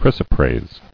[chrys·o·prase]